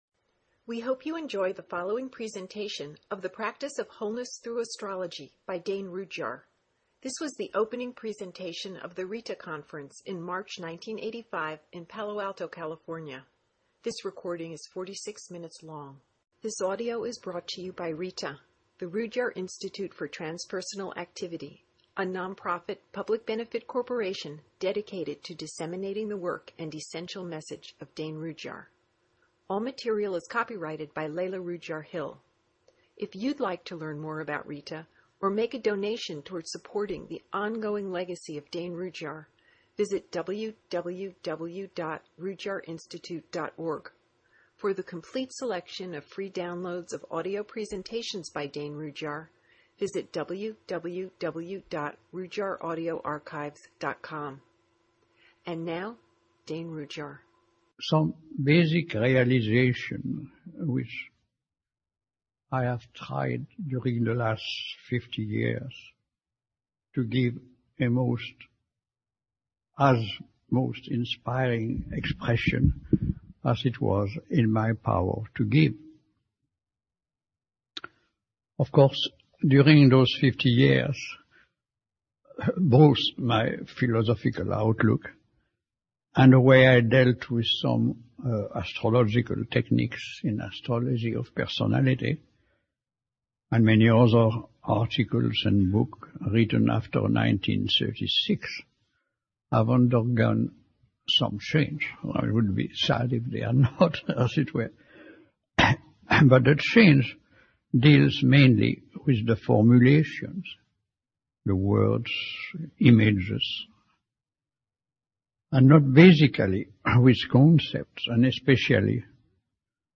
The Recordings of the 1985 Rudhyar Institute Conference